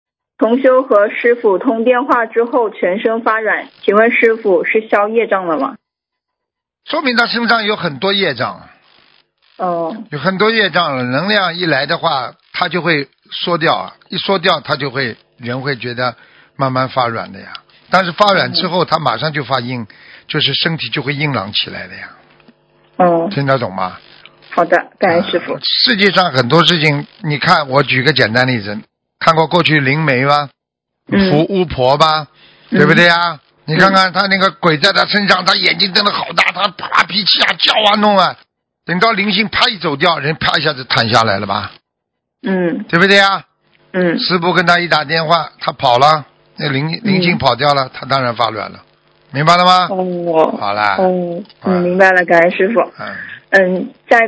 目录：☞ 2019年12月_剪辑电台节目录音_集锦